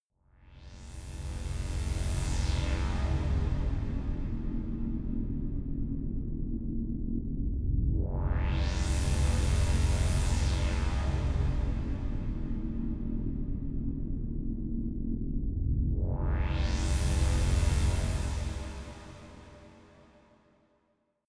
Gemafreie Musikelemente: Drones
Gemafreie Drones für Deine Projekte.